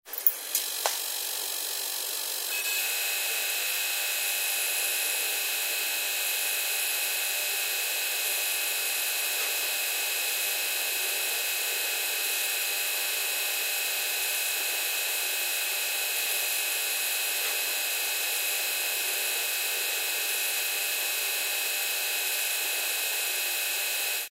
Electricidad estática de una televisión de plasma 01
electricidad estática
Sonidos: Hogar